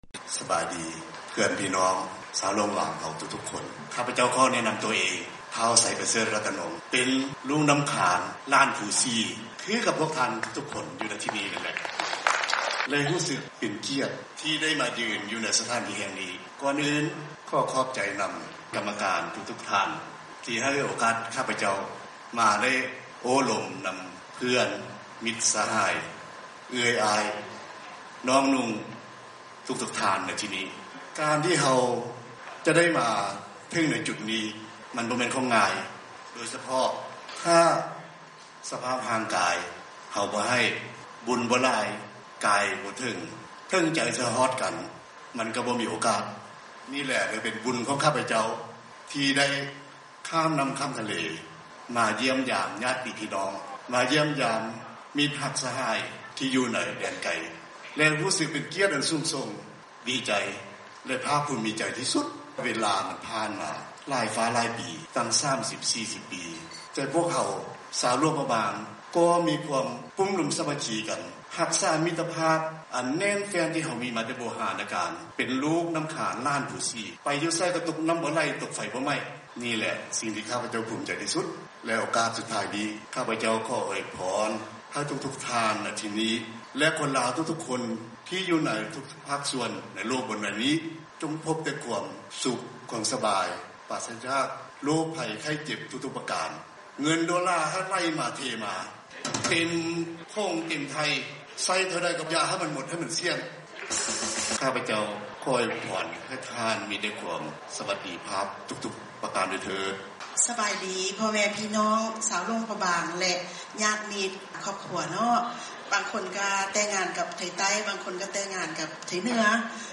ຟັງການກ່າວເປີດງານຂອງຜູ້ຕາງໜ້າຈາກຫຼາຍໆທະວີບ-11ທັນວາ14